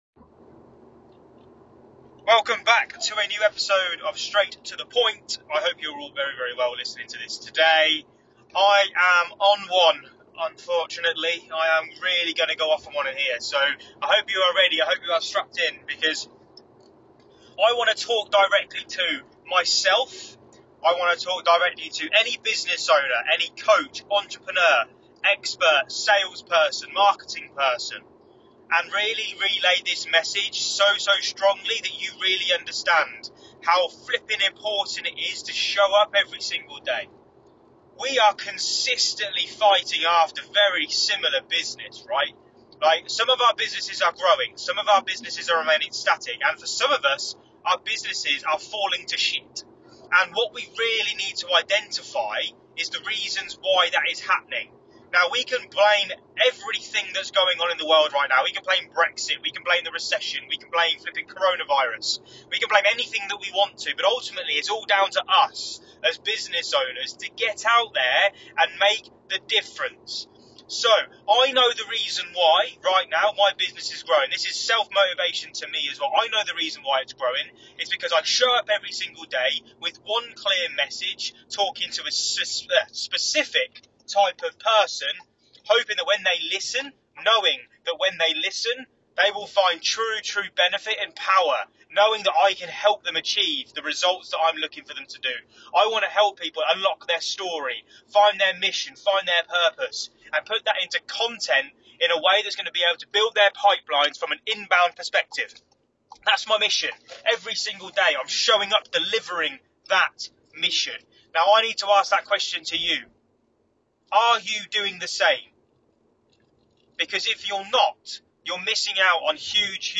In this episode I 100% have a rant to not just you lot, but myself aswell.